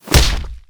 flesh2.ogg